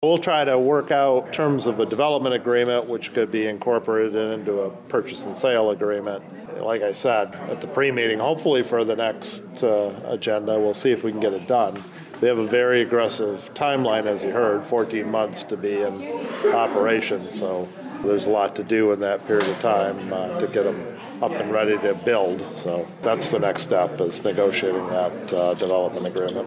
Administrator Elliott said that those steps could happen as soon as the Commission’s next meeting in two weeks…